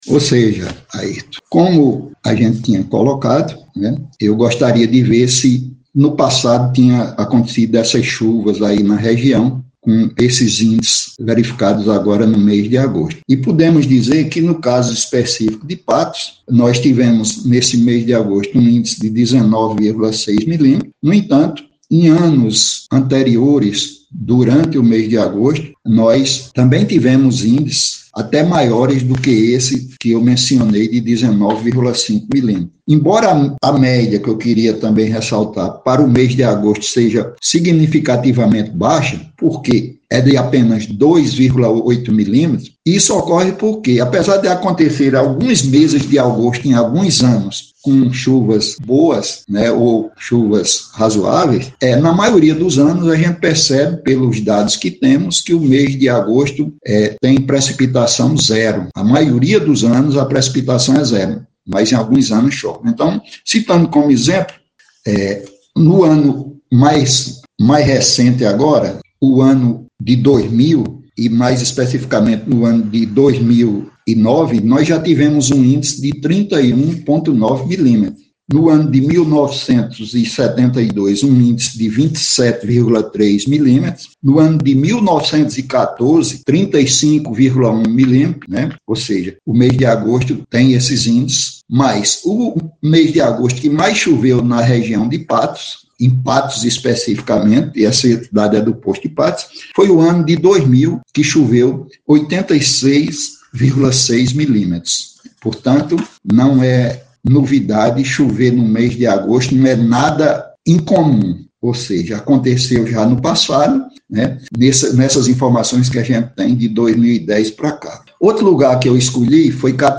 As informações foram repassadas pelo estudioso em meteorologia durante participação do programa Balanço da Notícia,  na Rádio Itatiunga FM 102,9.